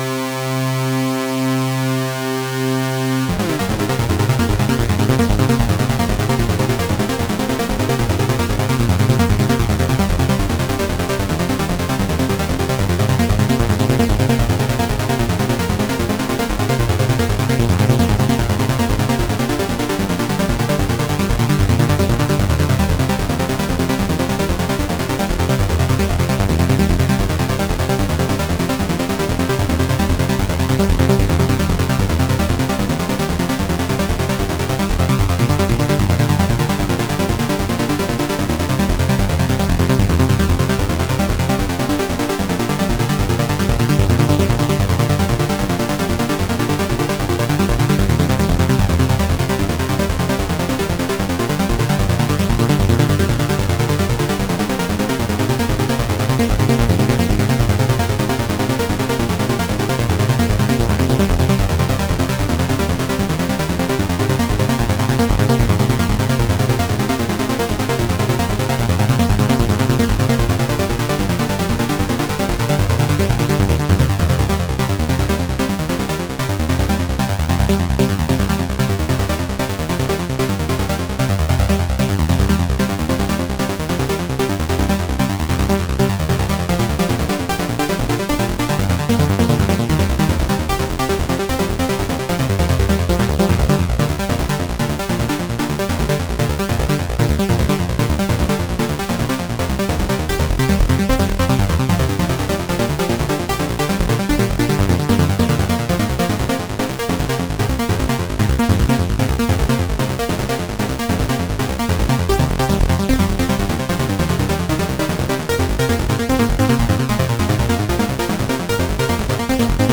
Experiments, Sketches, Drafts
A supersaw oscillator driven by a hyperactive arpeggiator, with a slathering of delay.